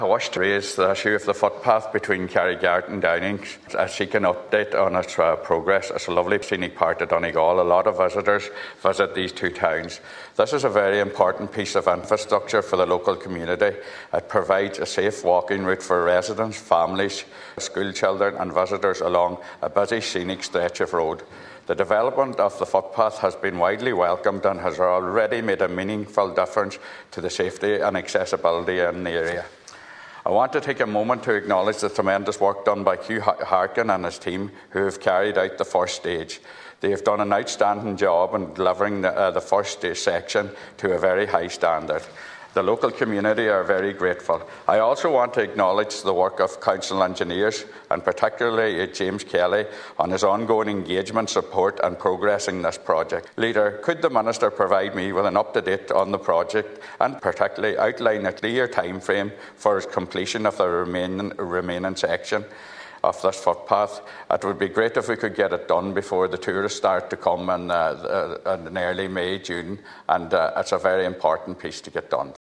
In the Seanad yesterday, Senator Manus Boyle asked the leader to seek a timeframe from that to happen………….